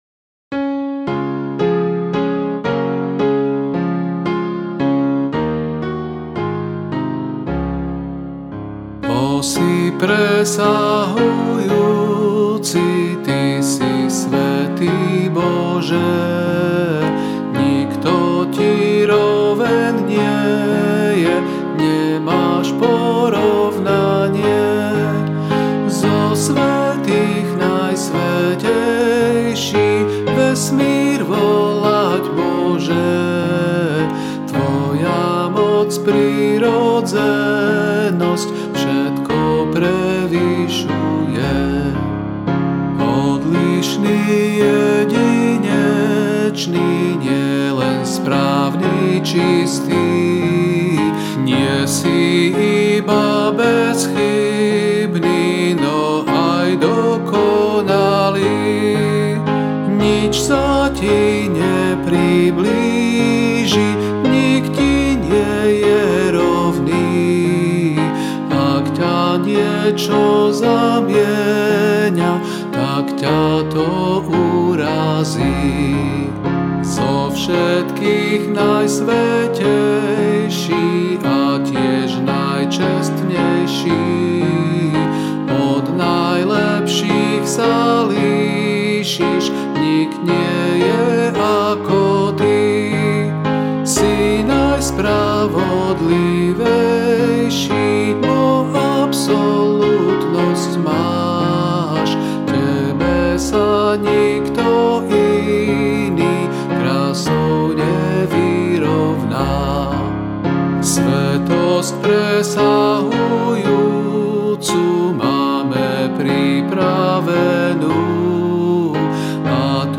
D大調